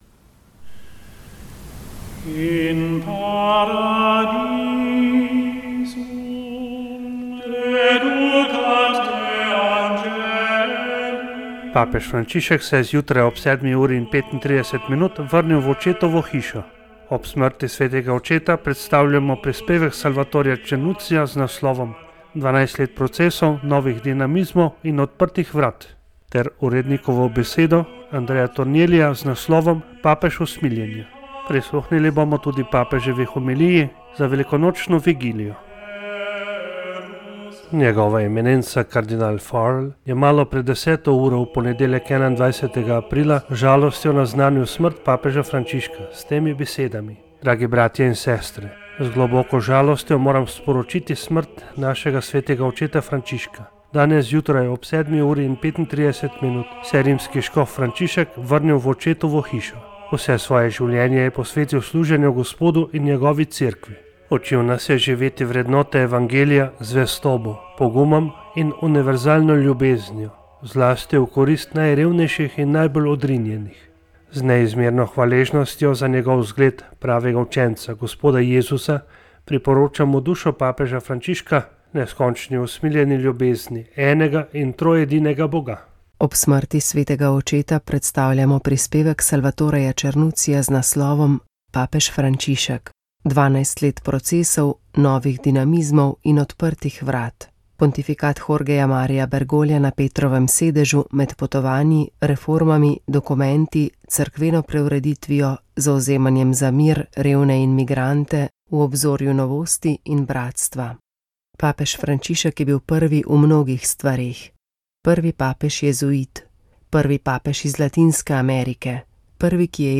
Pred nami je peta oddaja iz znanstvene konference z naslovom Med tradicijo in moderno: slovenski katoliški intelektualci in narodnostno vprašanje. V oddaji Moja zgodba ste lahko prisluhnili dvema primorskima duhovniškima primeroma.